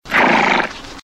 HORSE SNORTS.mp3
Original creative-commons licensed sounds for DJ's and music producers, recorded with high quality studio microphones.
horse_snorts_ujl.ogg